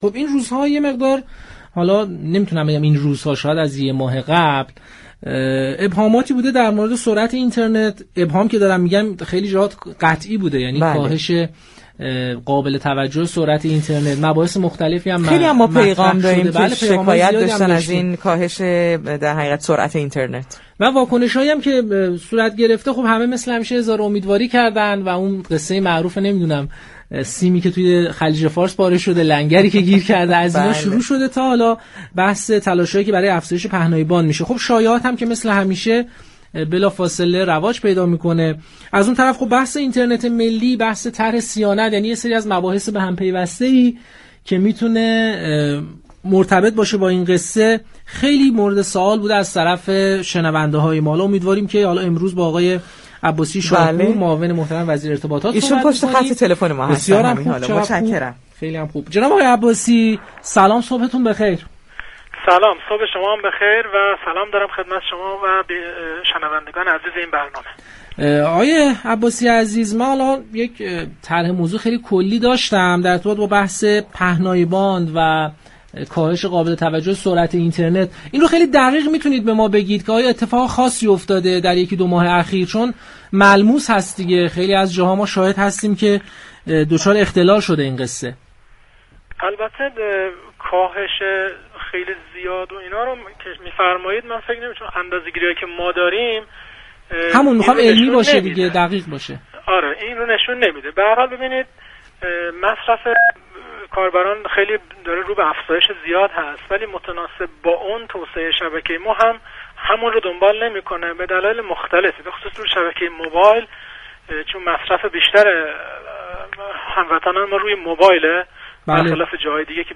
صادق عباسی شاهكوه معاون وزیر ارتباطات در گفتگو با پارك شهر رادیو تهران